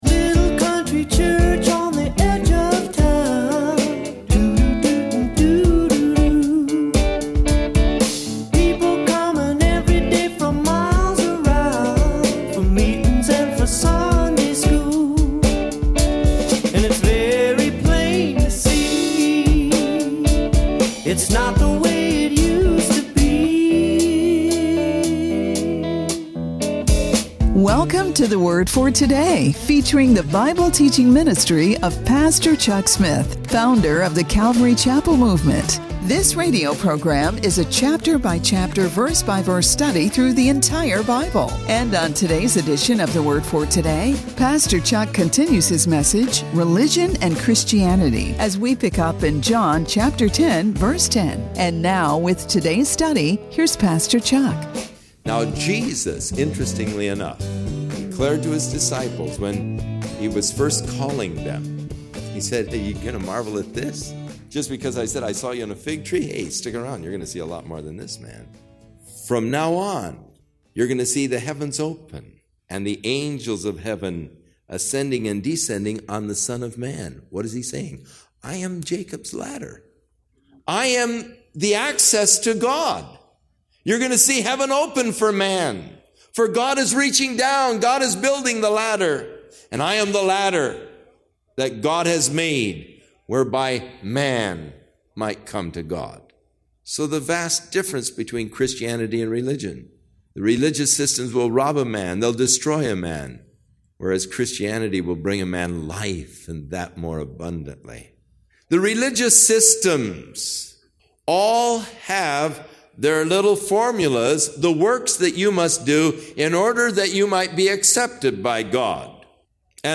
This radio program is a chapter-by-chapter verse-by-verse study through the entirevary Chapel Movement.